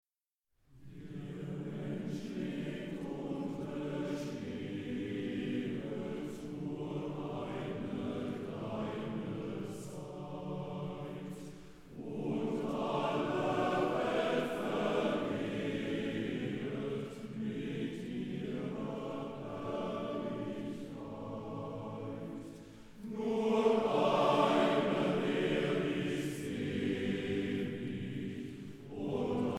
Erbaulich, feierlich und ehrwürdig